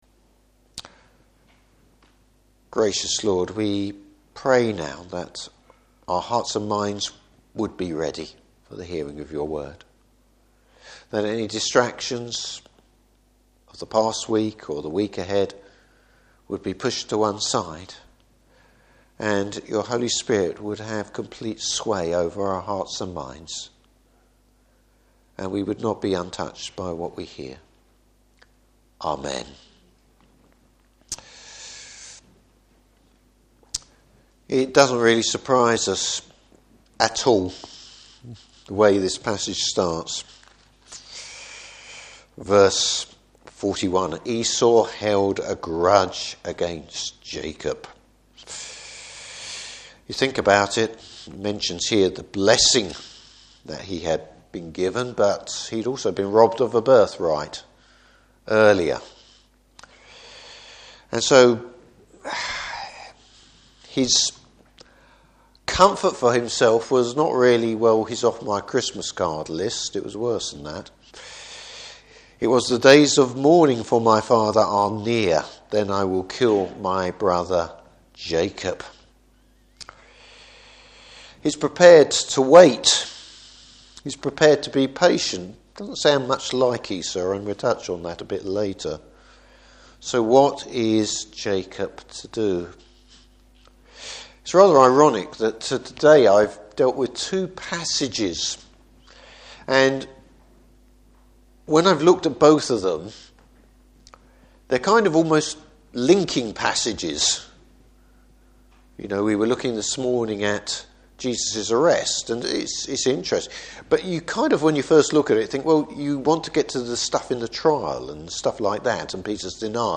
Service Type: Evening Service Three different attitudes to the Lord’s purposes.